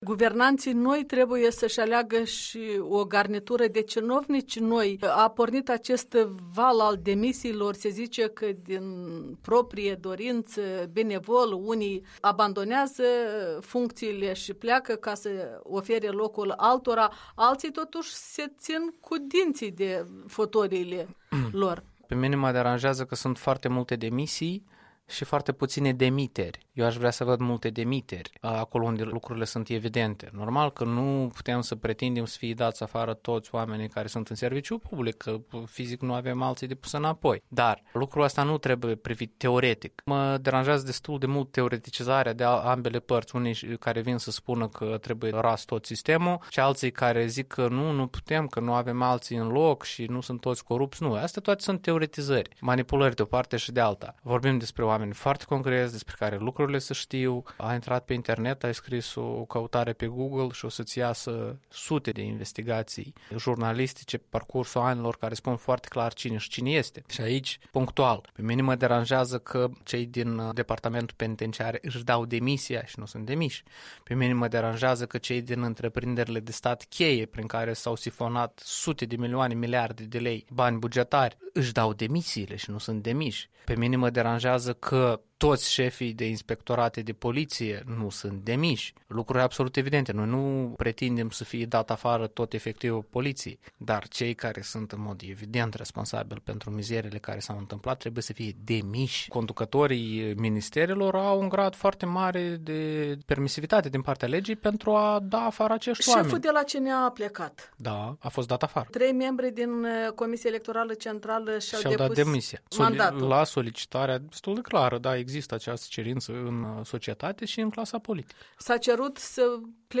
Un interviu cu activistul civic de la Chișinău.